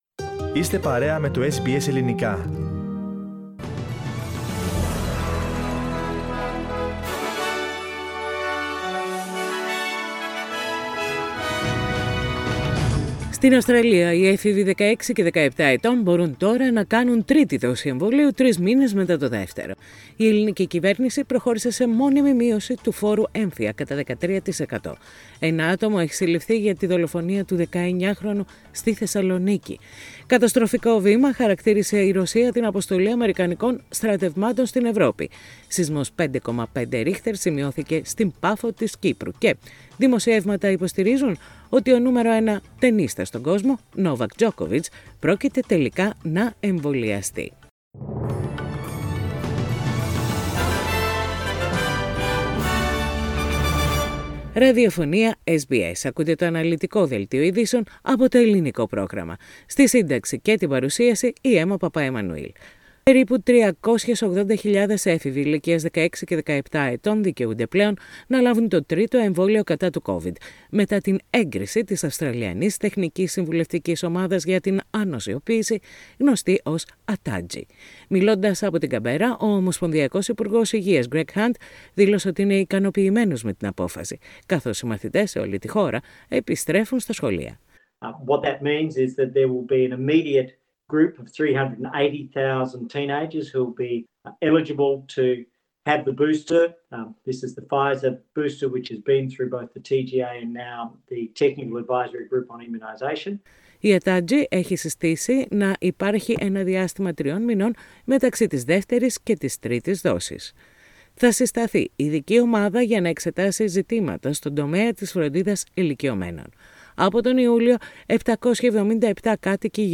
The detailed bulletin with the main news of the day, from Australia, Greece, Cyprus and the rest of the world.
News in Greek. Source: SBS Radio